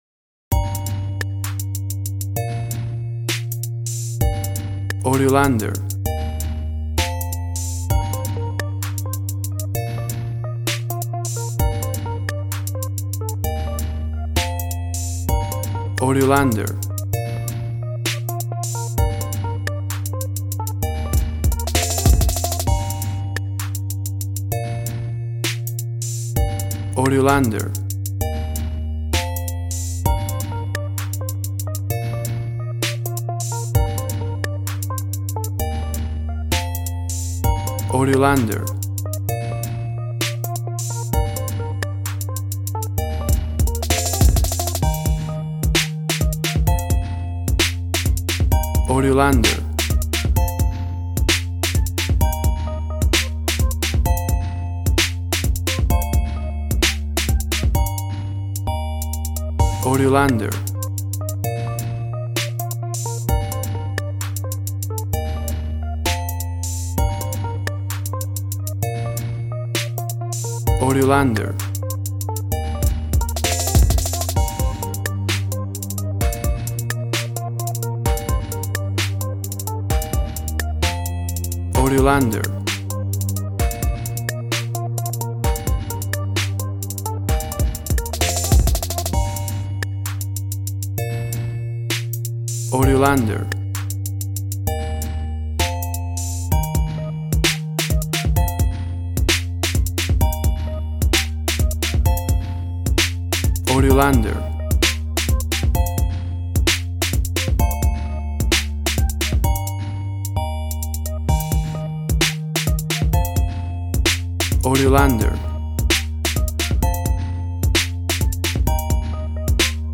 Tempo (BPM): 130